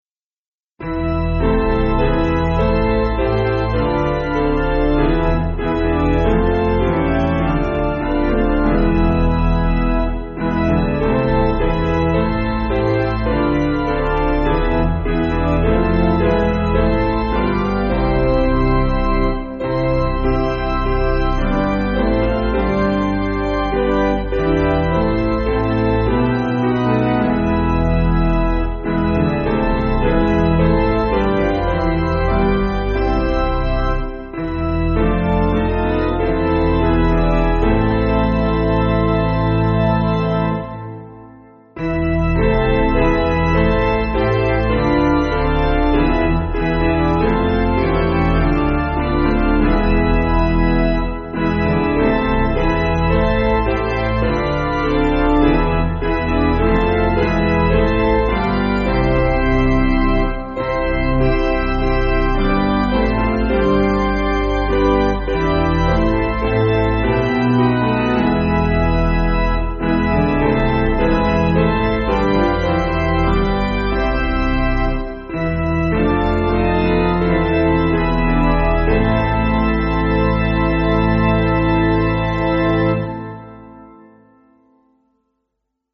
Basic Piano & Organ
(CM)   2/Am